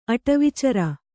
♪ aṭavicara